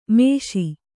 ♪ mēṣi